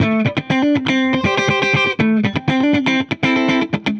Index of /musicradar/dusty-funk-samples/Guitar/120bpm
DF_70sStrat_120-A.wav